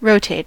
rotate: Wikimedia Commons US English Pronunciations
En-us-rotate.WAV